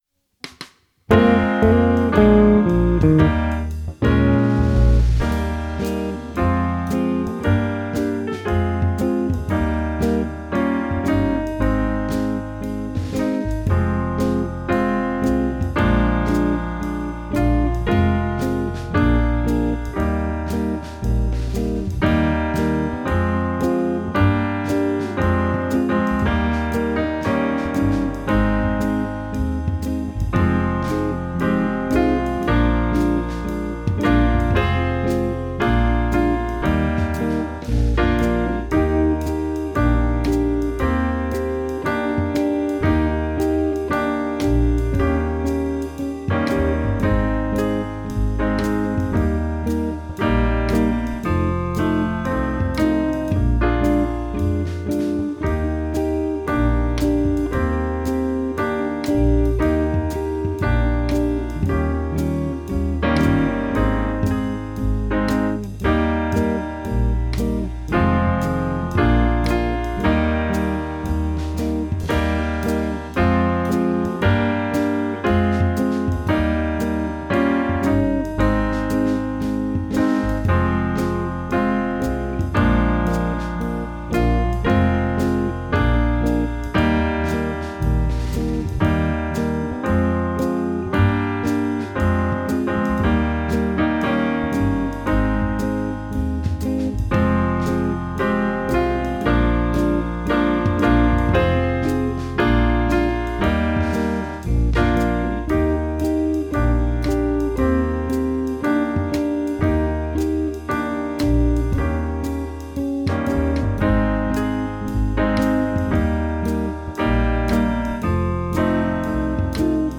• BONUS band track for exciting performances!
Backing tracks in mp3 format